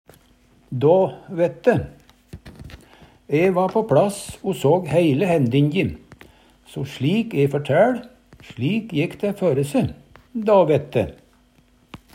då vette - Numedalsmål (en-US)